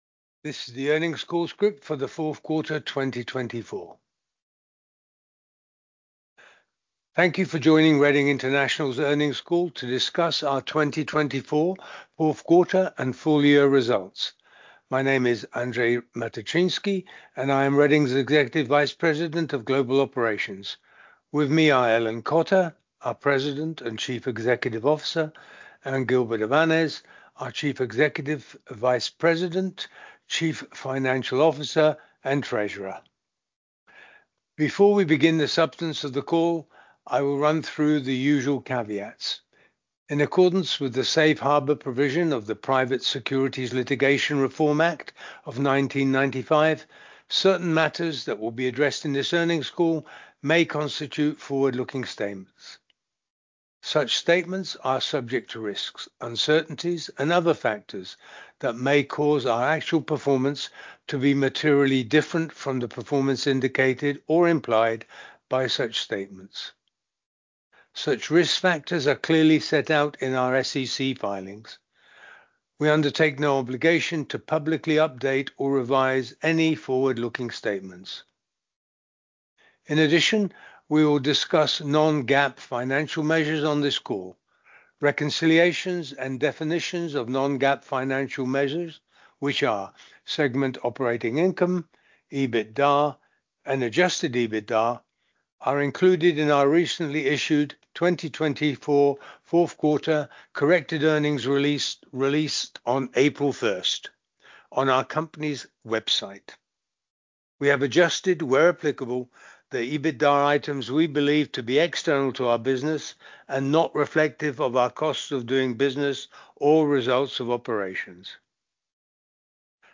Question and answer session will follow the formal remarks.